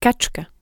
kačka.wav